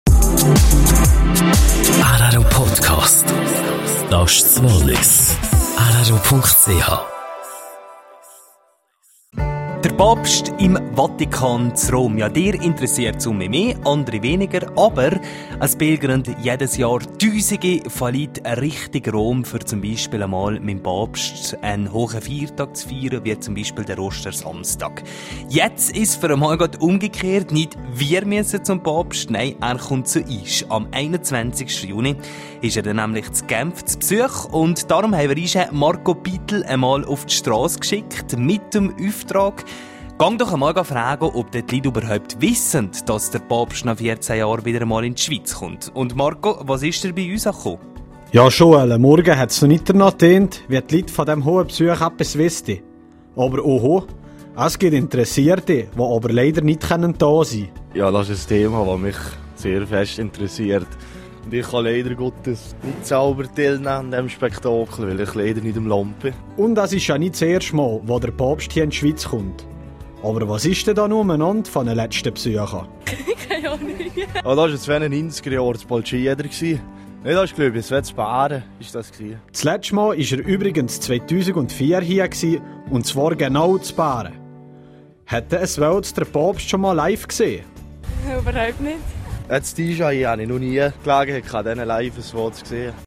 Papstbesuch in Genf: Strassenumfrage - Teil 2.